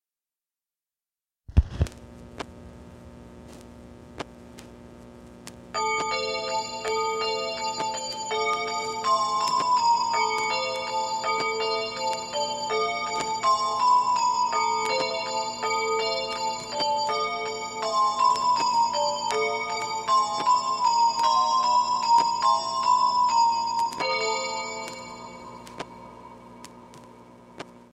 Žánr: Rock
Heavy rockabilly.